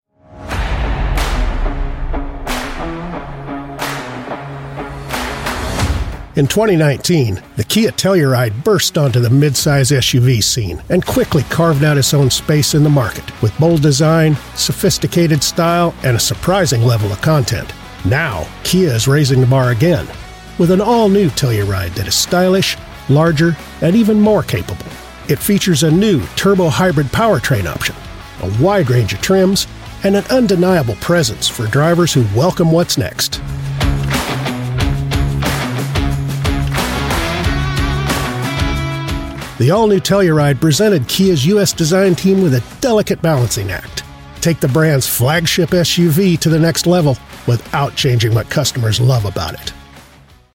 Adult (30-50) | Older Sound (50+)
0312Kia_Telluride_commercial.mp3